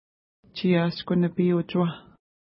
ID: 591 Longitude: -59.0645 Latitude: 53.6771 Pronunciation: tʃi:ja:sku-nəpi:-utʃuwa Translation: Gull Lake Mountains Feature: mountains Explanation: Named in reference to nearby lake Tshiashku-nipi (no 590).